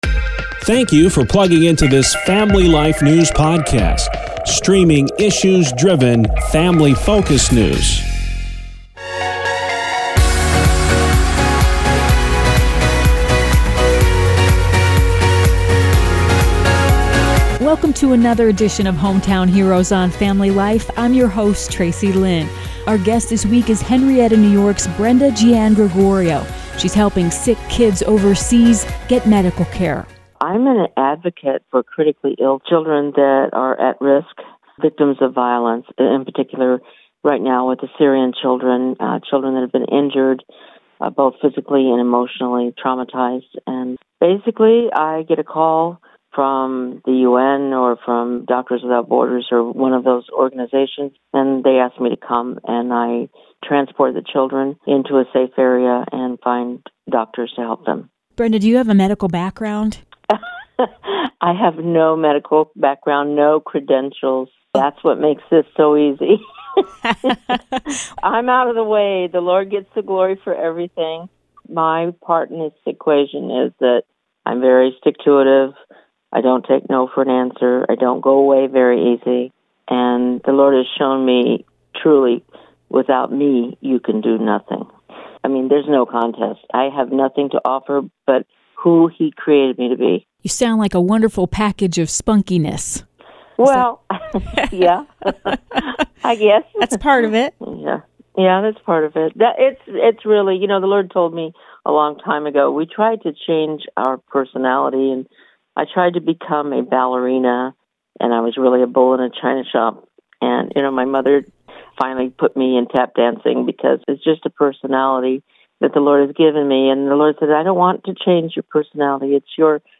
Podcast interview about Crossing Borders.